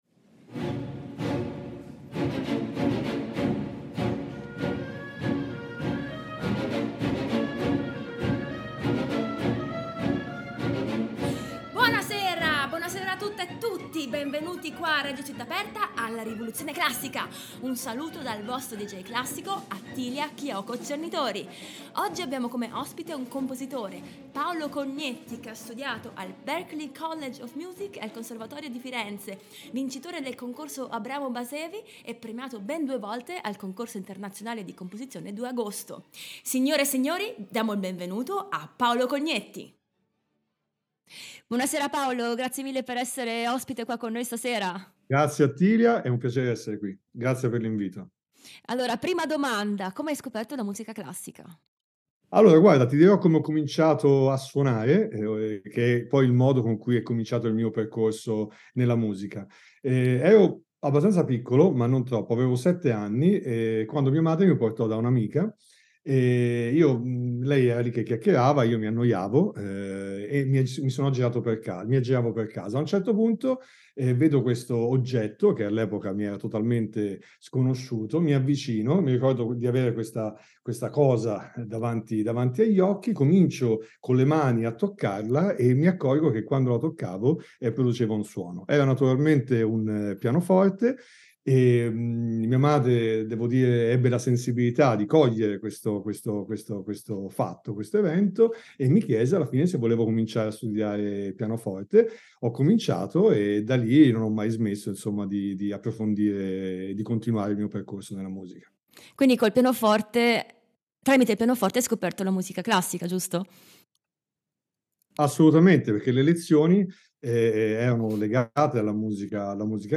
Ospite di questa puntata il compositore